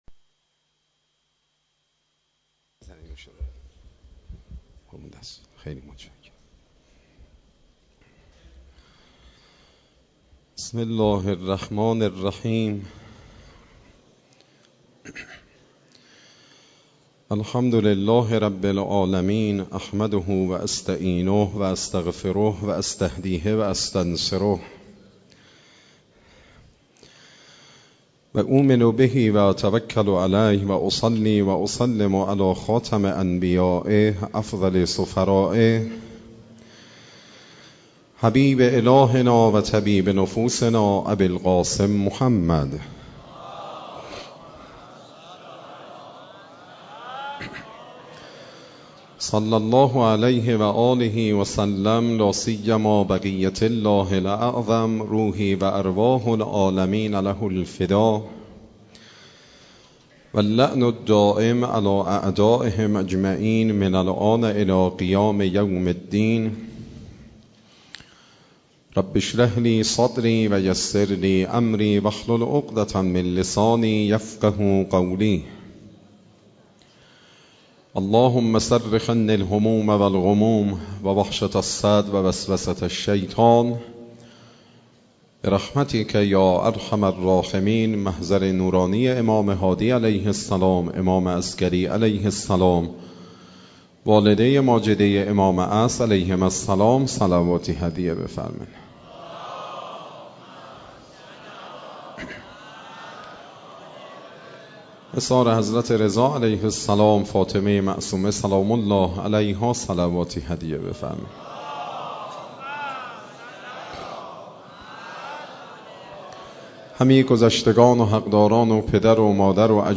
دهه آخر صفر97 - شب دوم - مسجد اعظم قم